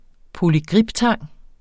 Udtale [ polyˈgʁibˌtɑŋˀ ]